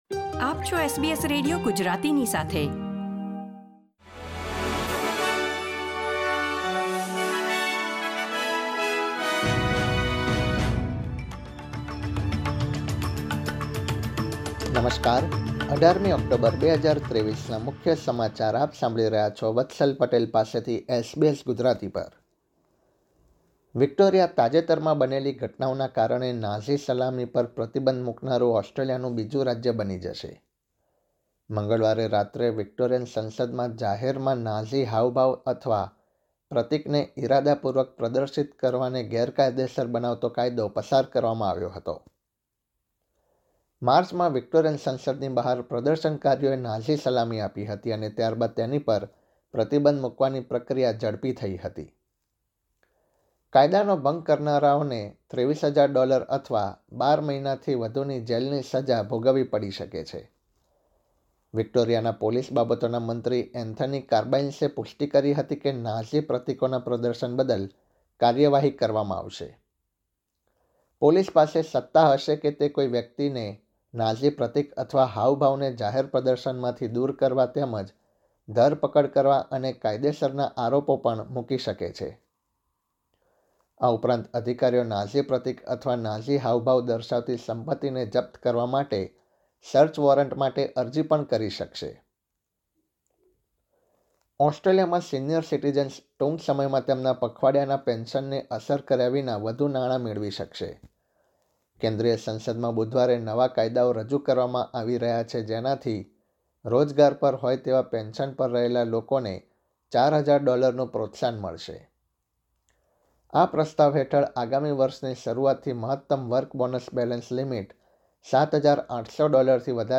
SBS Gujarati News Bulletin 18 October 2023